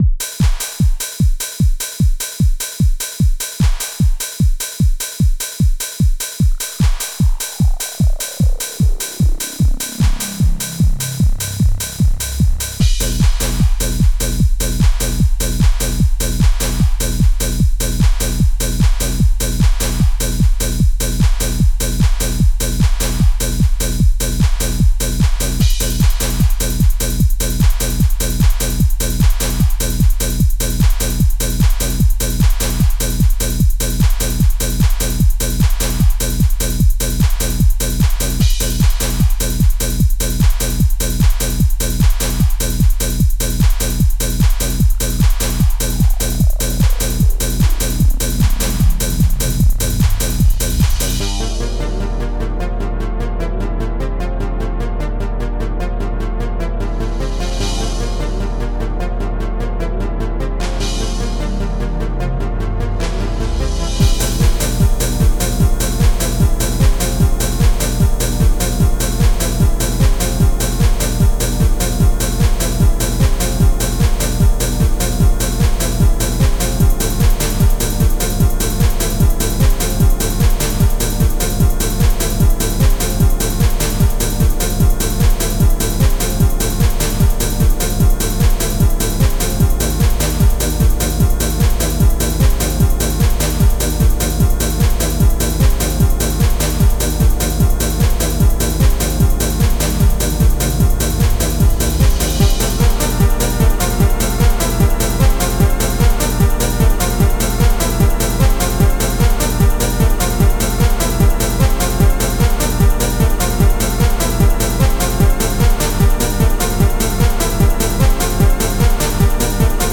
- trance, dream, rmx